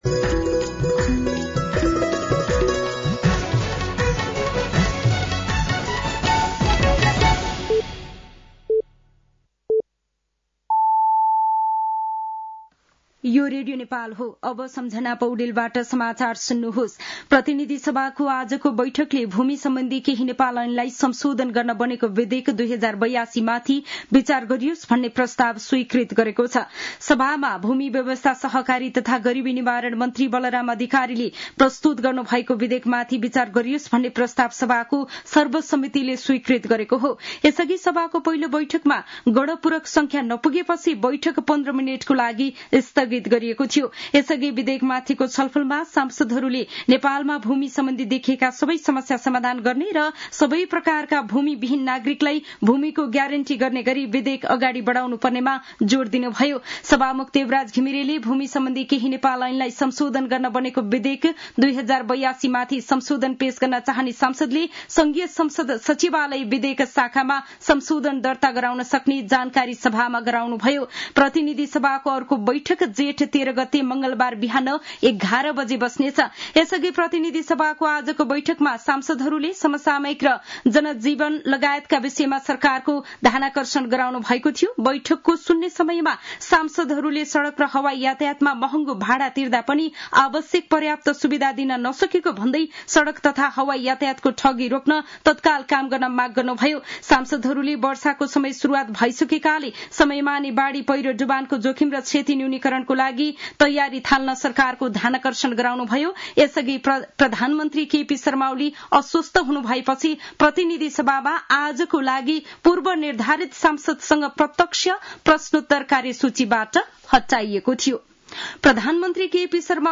साँझ ५ बजेको नेपाली समाचार : ६ जेठ , २०८२